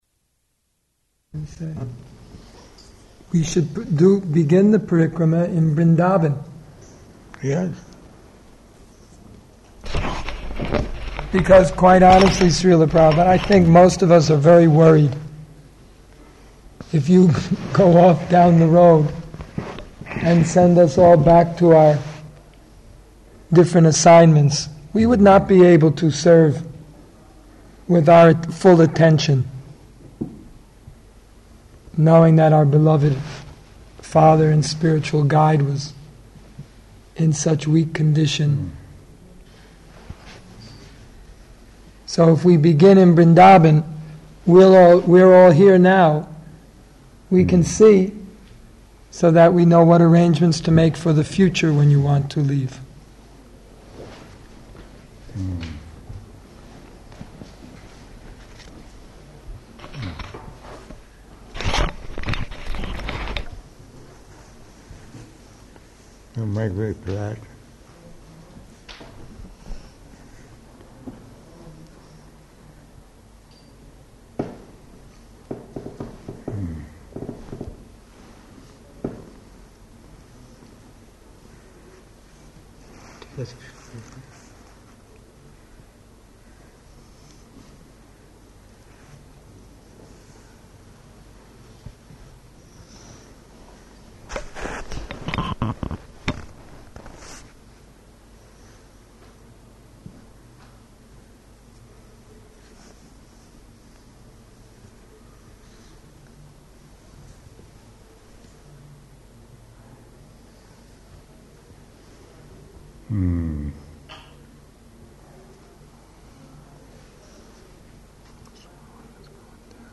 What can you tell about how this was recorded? -- Type: Conversation Dated: November 10th 1977 Location: Vṛndāvana Audio file